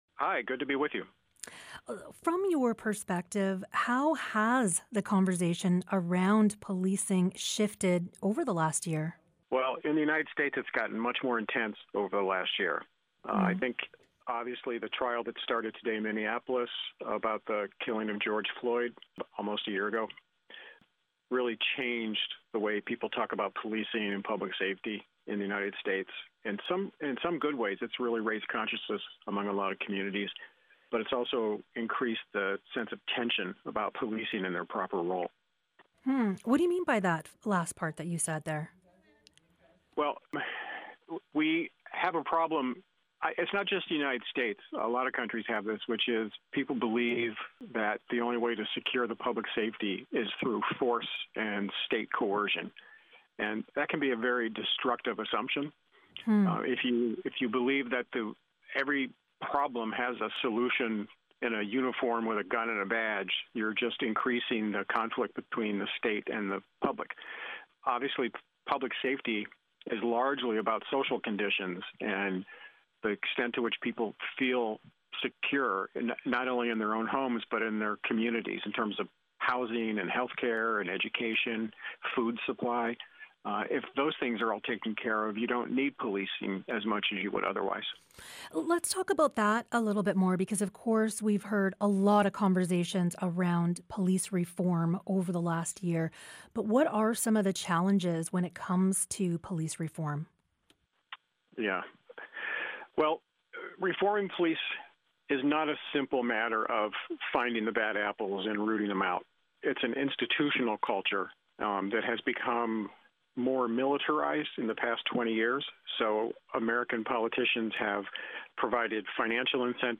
Audio, Broadcast